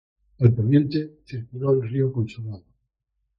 Read more Adj Noun Frequency 44k Hyphenated as po‧nien‧te Pronounced as (IPA) /poˈnjente/ Etymology Inherited from Latin pōnēns In summary Inherited from Latin pōnentem.